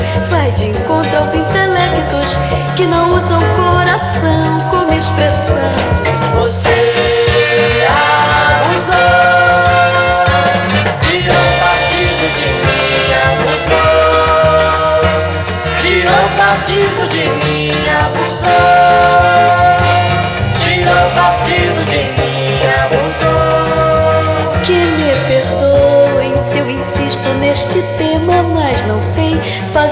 MPB Brazilian